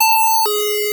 RetroGamesSoundFX / Alert / Alert12.wav
Alert12.wav